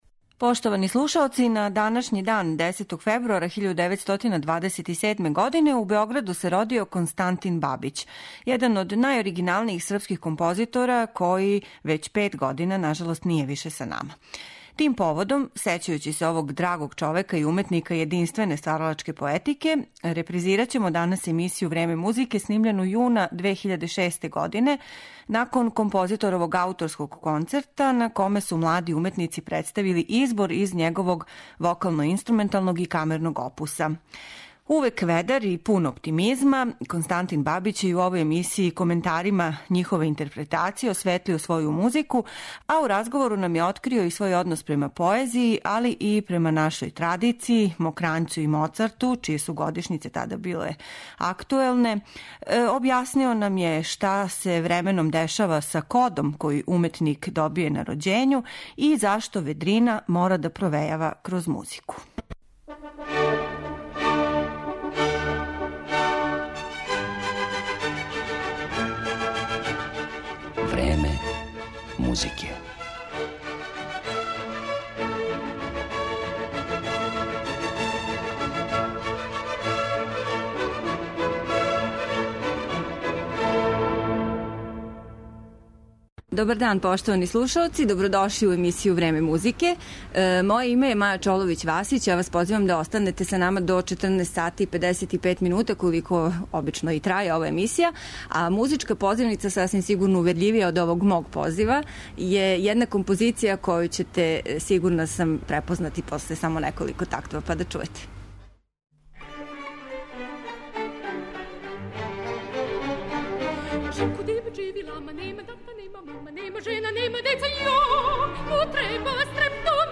Увек ведар и пун оптимизма, Бабић је и у овој емисији коментарима осветлио своју музику, али и домаће стваралaштво, наш однос према традицији и низ других занимљивих тема.